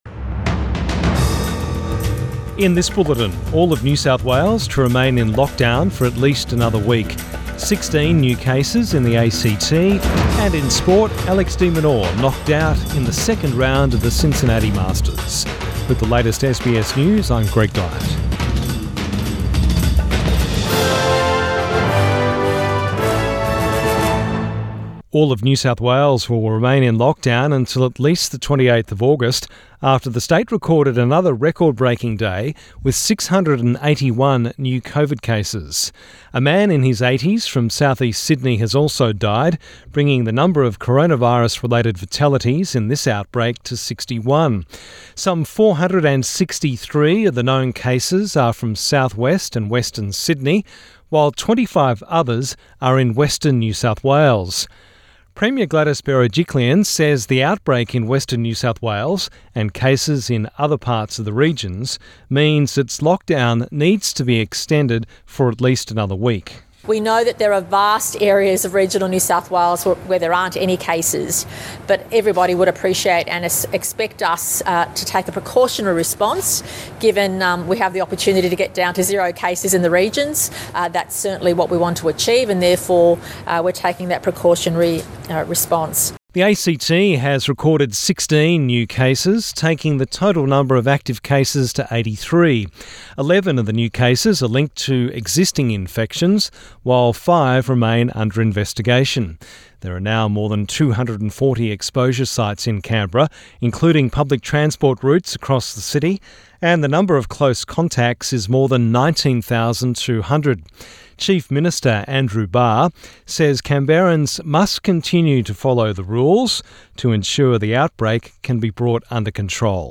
PM bulletin 19 August 2021